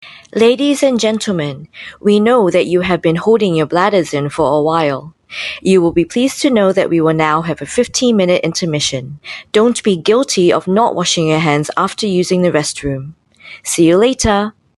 EN Asian
female